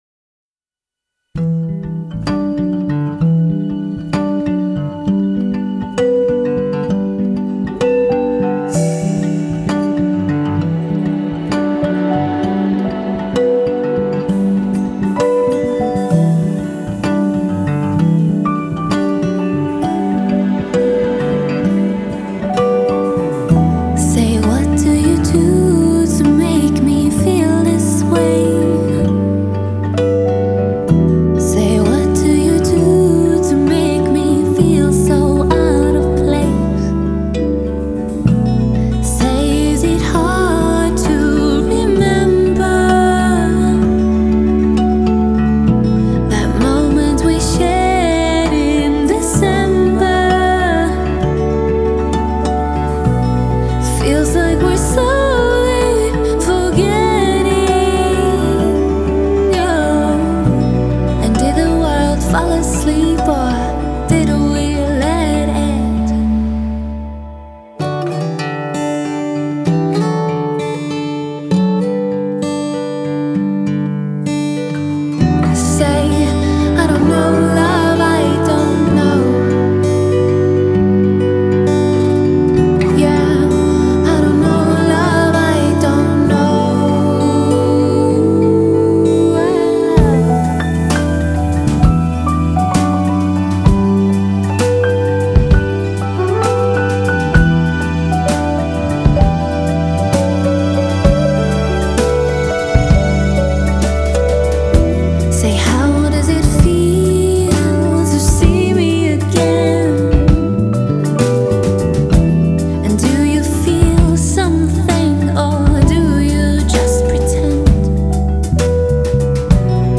Genre: indie / pop / rock